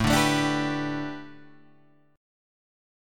A9sus4 chord